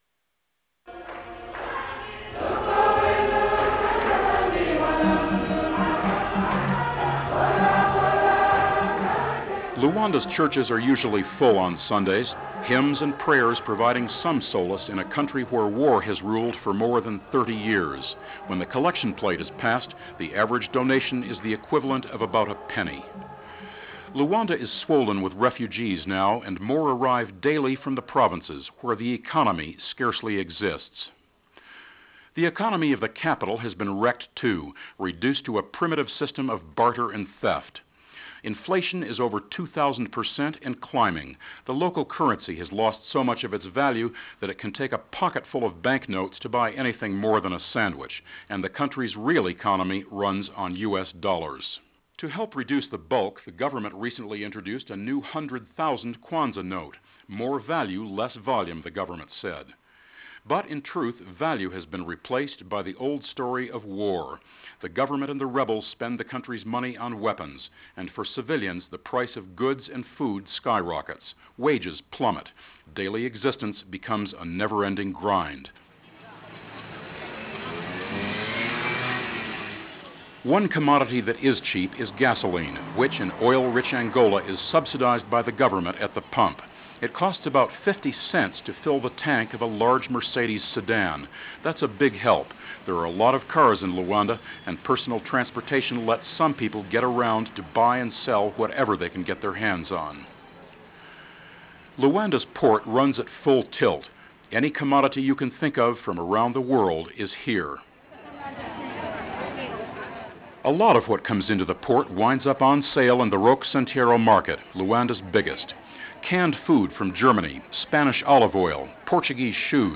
The report was produced by Monitor Radio and originally broadcast in February of 1994.